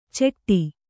♪ ceṭṭi